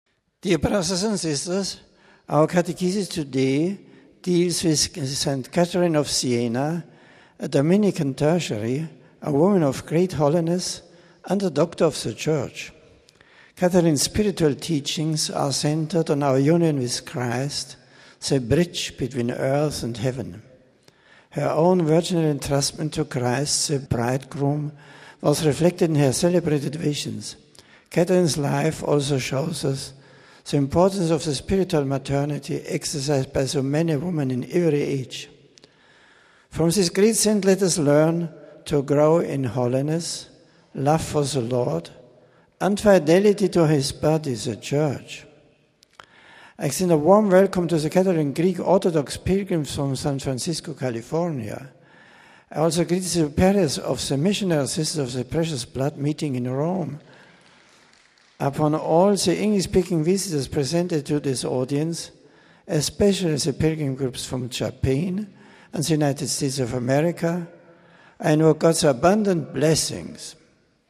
The general audience of November 24 was held in the Vatican’s Paul VI Audience Hall. A scripture passage was read in several languages. An aide greeted the Pope on behalf of the English-speaking pilgrims, and presented the various groups to him. Pope Benedict then delivered a discourse in English.